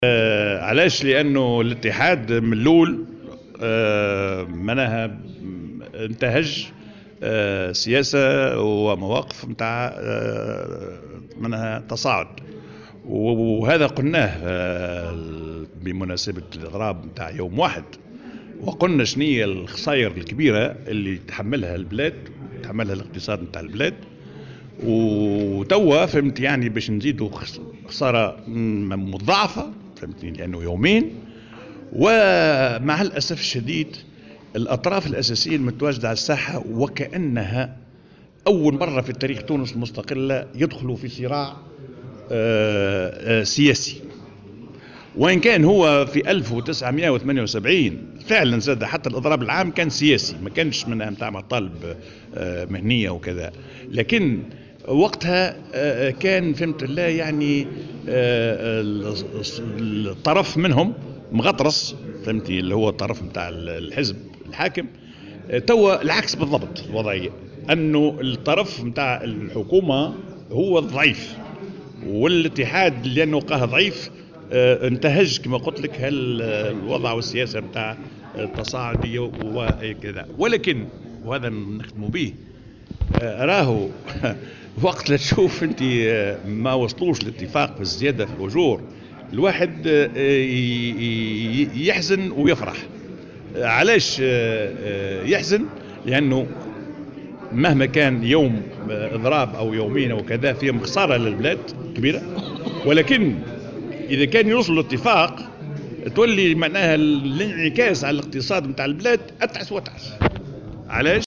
في المقابل، اعتبر الديماسي في تصريح لمراسل "الجوهرة اف ام" على هامش ندوة في المنستير، أنه في صورة التوصّل لاتفاق حول الزيادة الأجور فإن التداعيات ستكون وخيمة على اقتصاد البلاد، وفق ترجيحه.